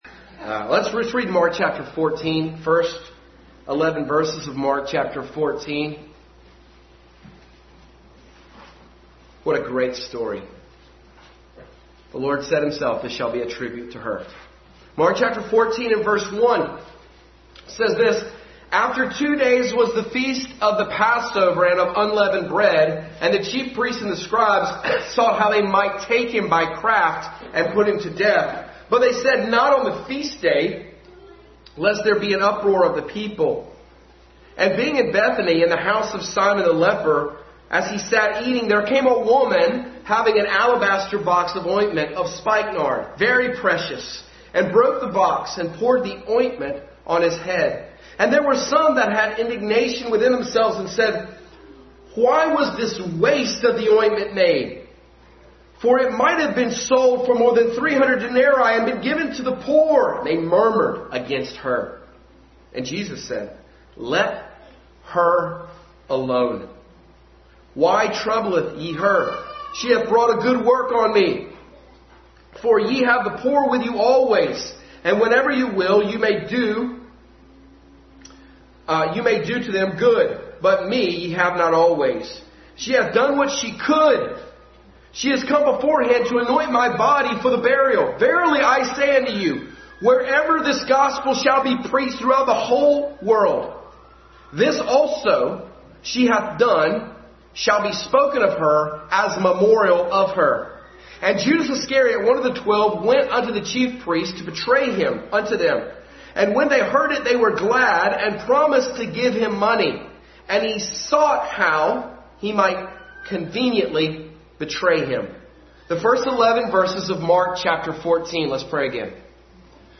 Mark 14:1-11 Passage: Mark 14:1-11, 1 Corinthians 16:1-2, Exodus 25:1-2, John 12:5, Exodus 21:32 Service Type: Family Bible Hour Family Bible Hour message.